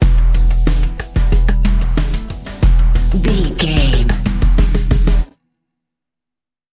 Aeolian/Minor
synthesiser
drum machine
hip hop
Funk
neo soul
acid jazz
energetic
cheerful
bouncy
Triumphant
funky